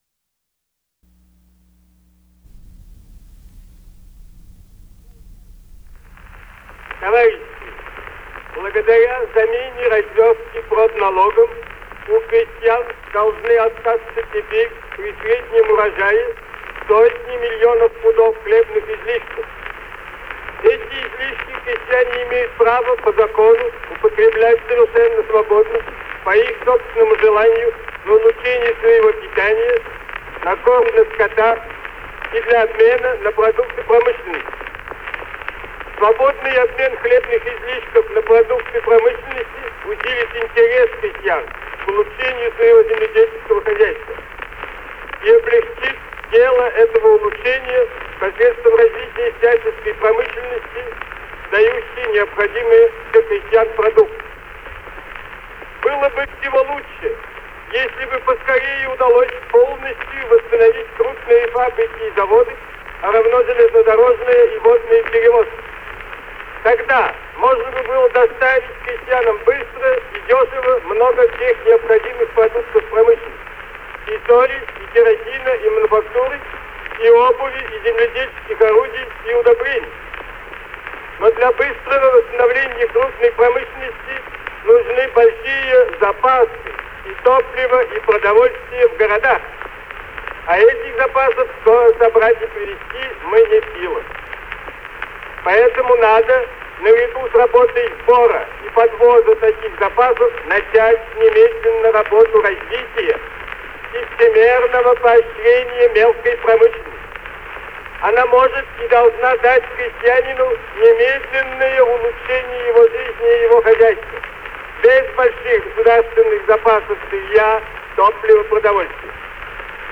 Lenin delivers a speech about a food tax, a tax-in-kind, and what the Soviets should do in case of grain overproduction